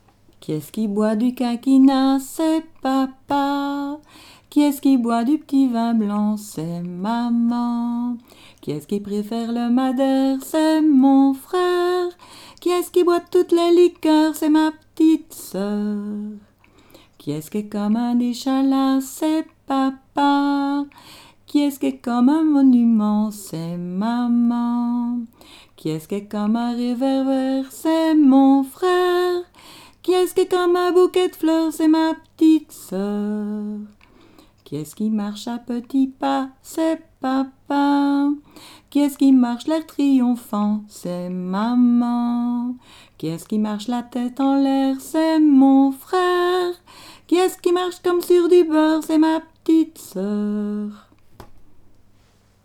Genre : chant
Type : chanson d'enfants
Aire culturelle d'origine : Haute Ardenne
Lieu d'enregistrement : Malmedy